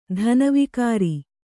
♪ dhana vikāri